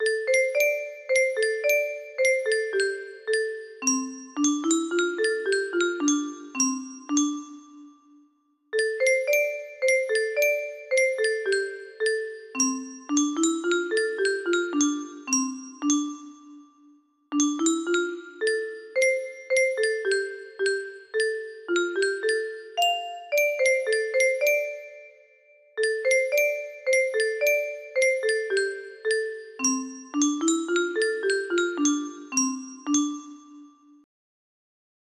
The Foggy Dew music box melody